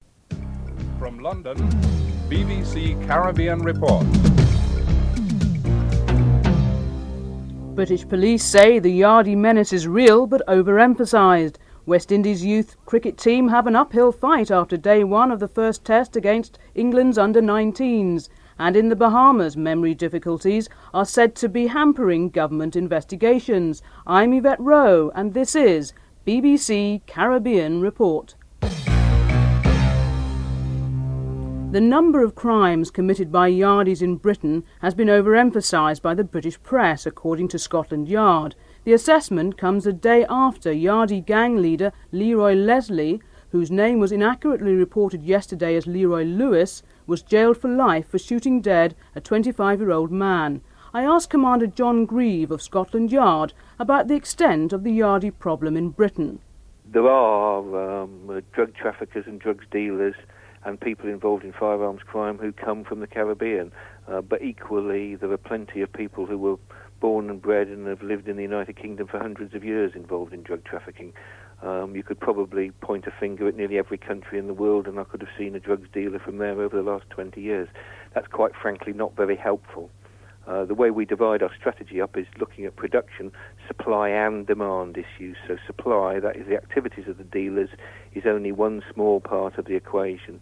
1. Headlines (00:00-00:38)
Interview with Baroness Cumberledge, Junior Health Minister (10:56-12:25)